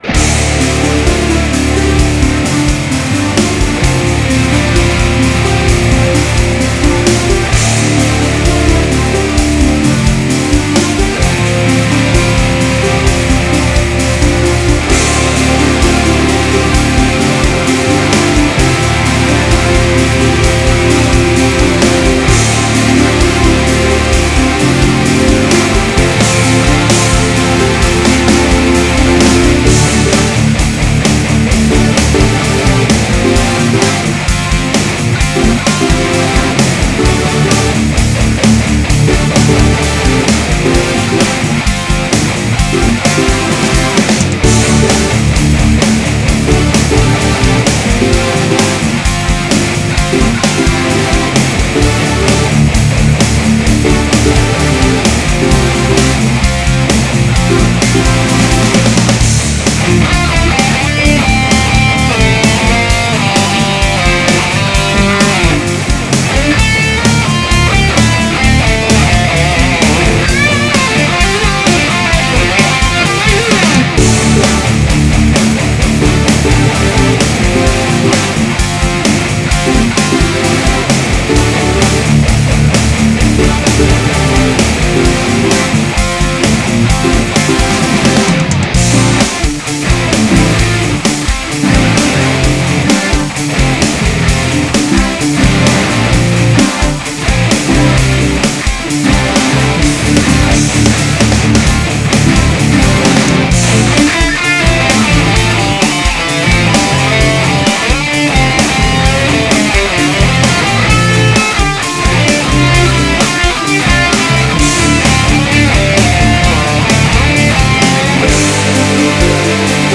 (strumentale)